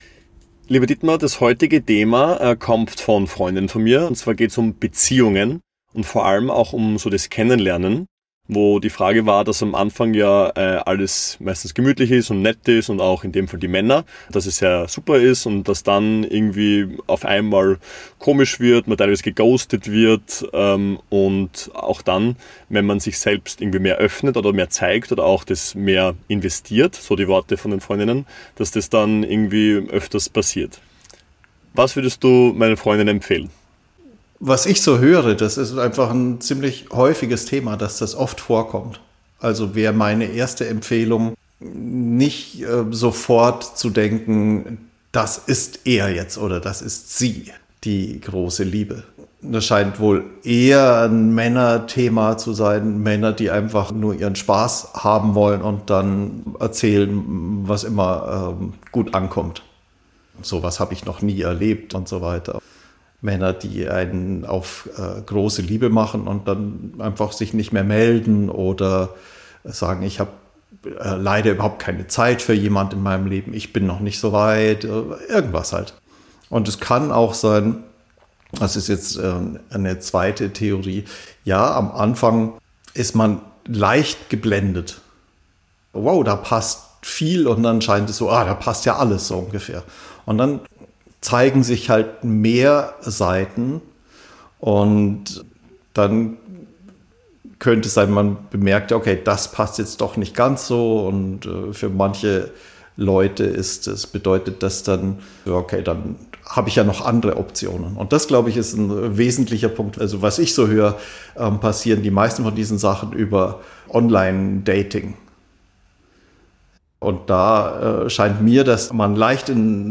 Re-Source – Gespräch 8.